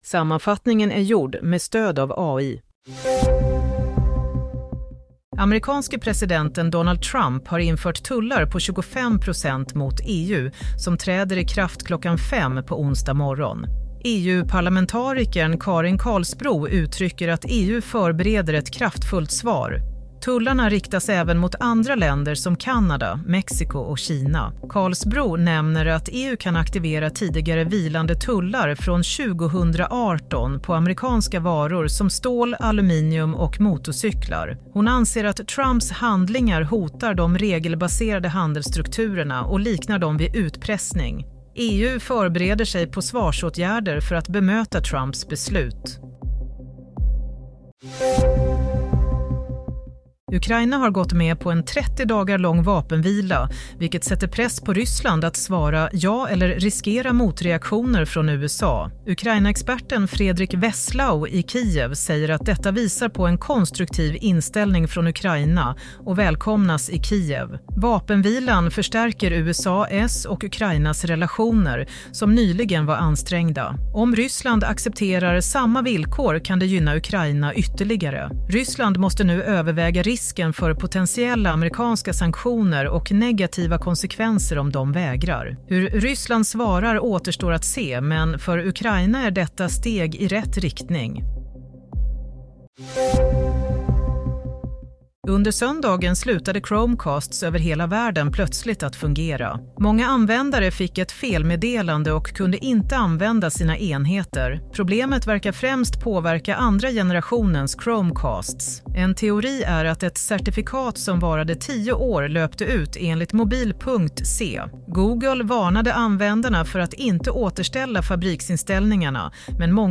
Nyhetssammanfattning – 12 mars 07:00
Sammanfattningen av följande nyheter är gjord med stöd av AI. – EU redo för motattack mot Trump: Förbereder egna tullar – Experten: ”Ukraina och USA har nu hittat tillbaka till varandra” – Larmet från Google: Det ska du INTE göra med din Chromecast